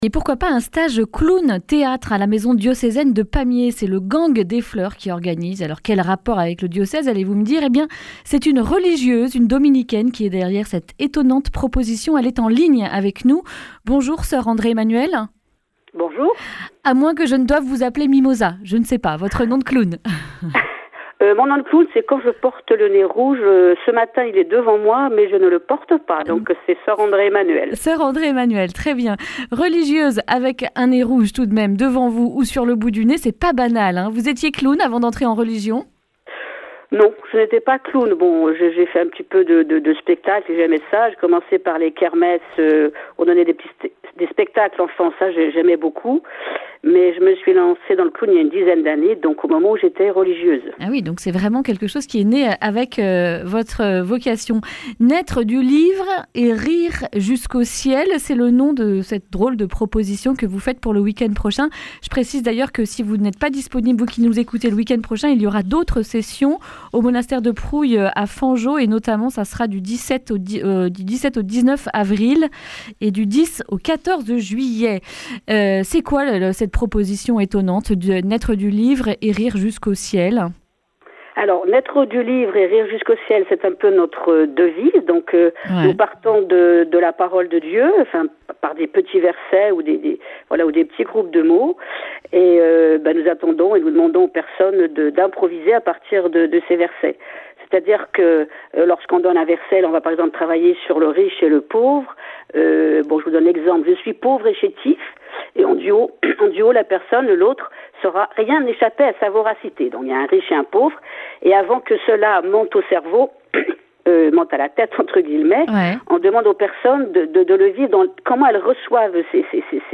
mardi 18 février 2020 Le grand entretien Durée 10 min
Une émission présentée par